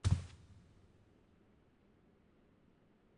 FootstepHandlerFabric7.wav